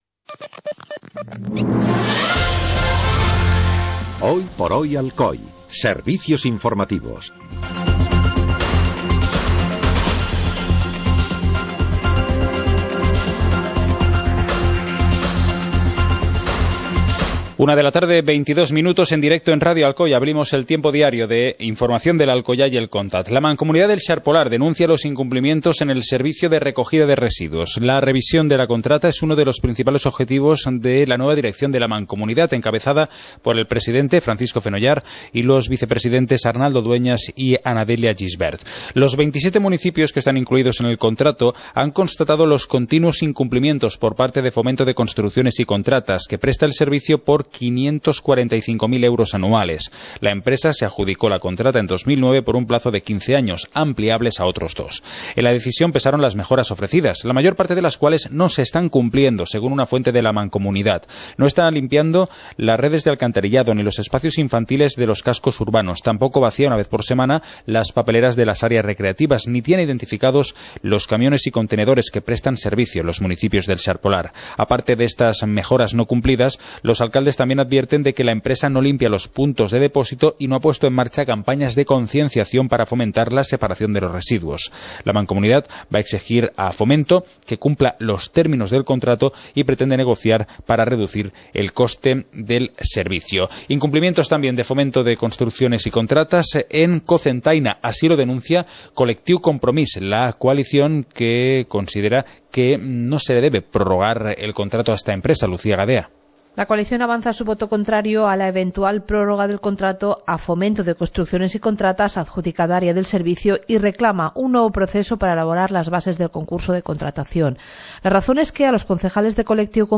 Informativo comarcal - viernes, 13 de noviembre de 2015